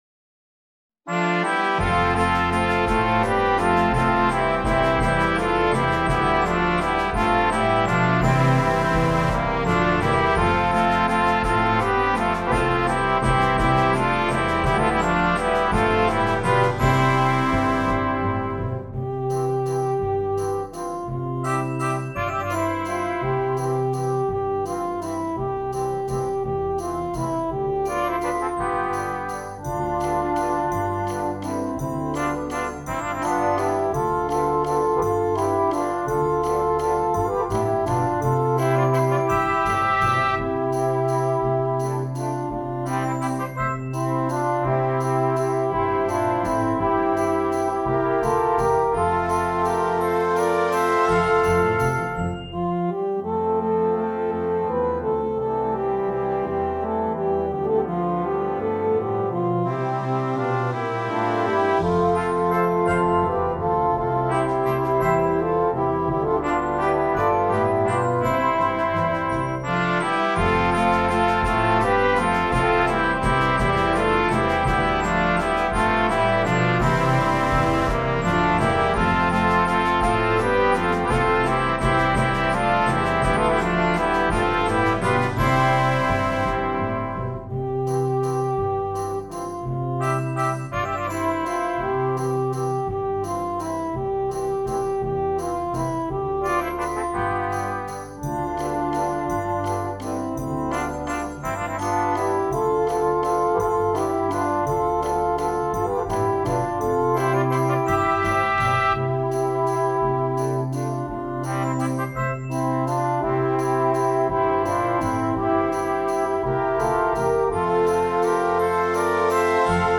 Brass Choir
Traditional Mexican Folk Song